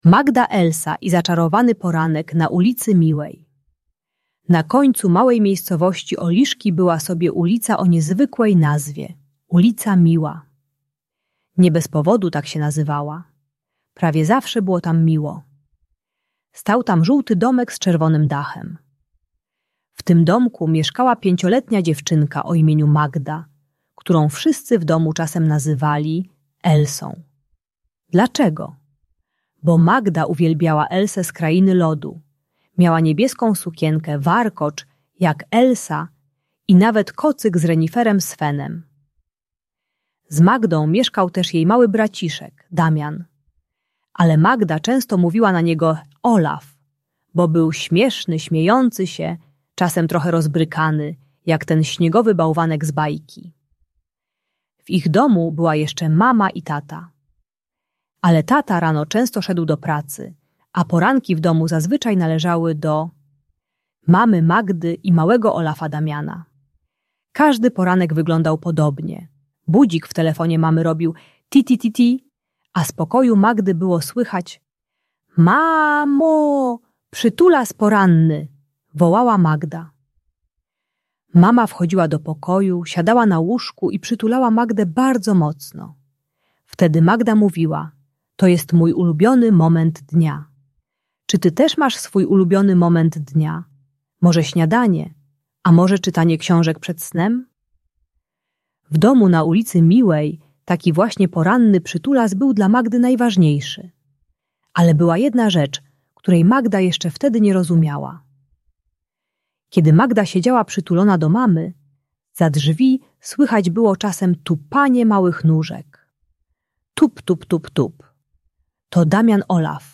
Bajka dla dziecka które bije rodzeństwo, przeznaczona dla przedszkolaków 4-6 lat. Ta audiobajka o rywalizacji między rodzeństwem i zazdrości o mamę uczy techniki głębokiego oddechu i nazywania emocji zamiast bicia. Pomaga dziecku które jest zazdrosne o młodsze rodzeństwo i reaguje agresją gdy musi dzielić się uwagą rodzica.